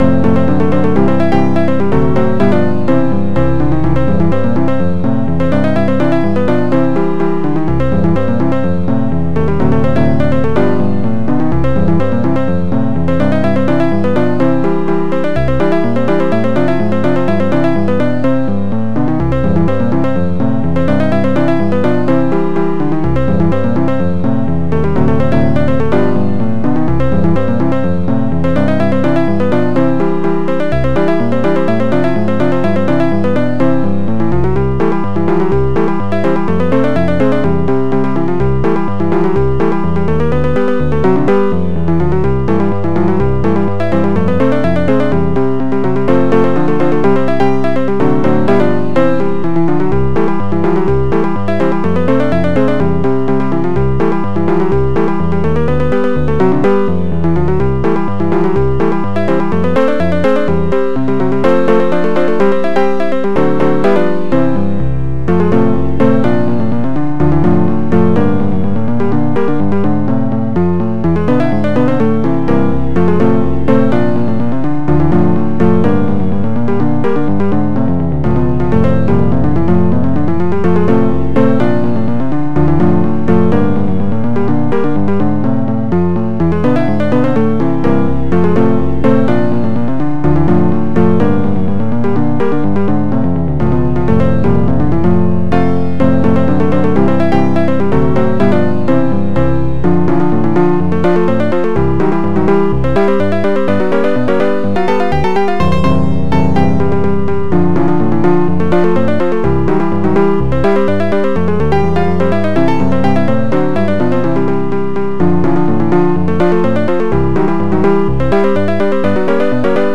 Tracker DSIK DSM-format Tracks 4 Samples 31 Patterns 19